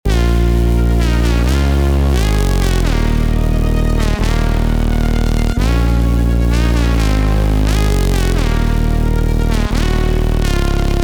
dnb melodic expansion
Astridion Kit D#min (Full)